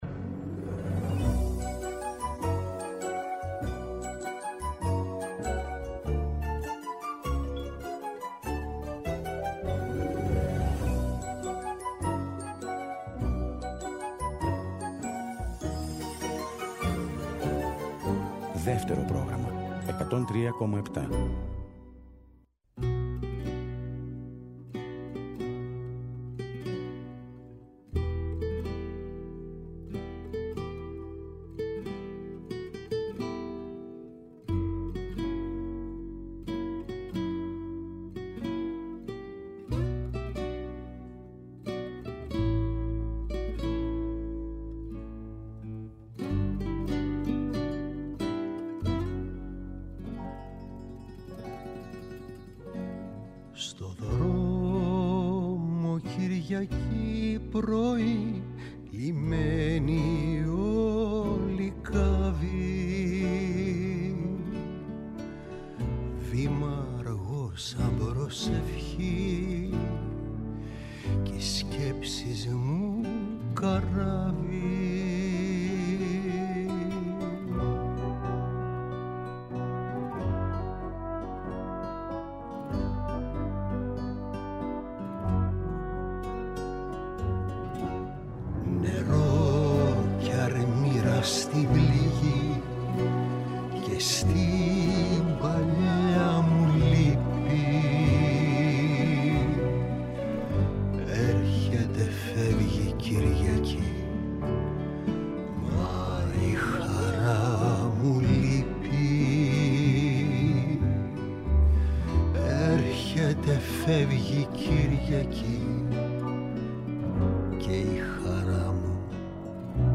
συνομιλεί τηλεφωνικά με τον Βασίλη Λέκκα
Συνεντεύξεις